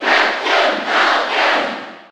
Category:Crowd cheers (SSB4) You cannot overwrite this file.
Captain_Falcon_Cheer_German_SSB4.ogg